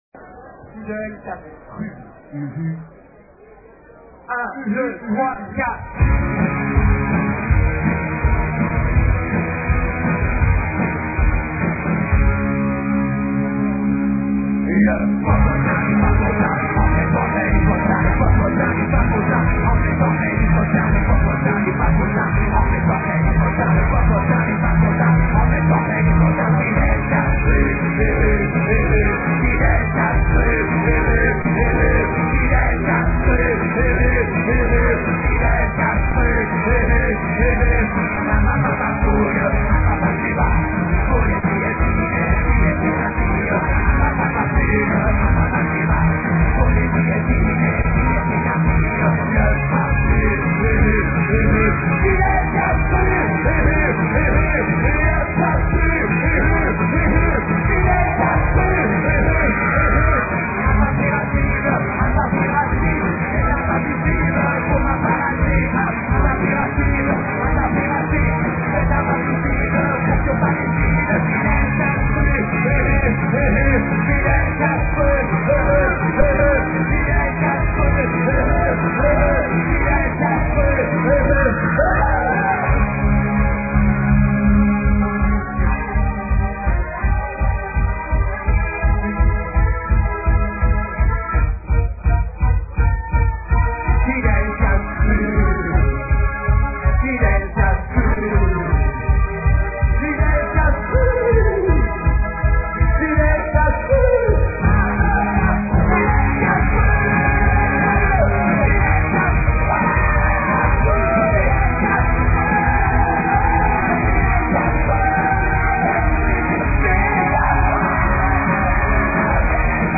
Les fichiers sont en mp3 et de qualité assez mauvaise .
Une superbe chanson live